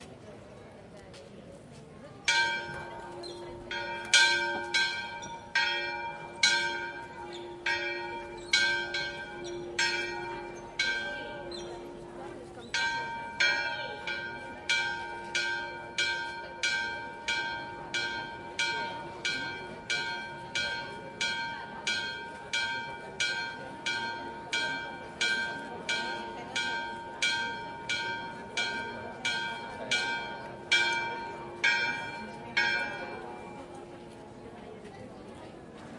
描述：在Baiona（Pontevedra）的Madres Dominicas修道院录制质量振铃。2015年8月15日，18：46：33。MS侧微观水平：90度的角度幅度。
Tag: 钟声 Madres-Dominicas 振铃换质量 巴约纳 现场录音 VAL-Minhor 修道院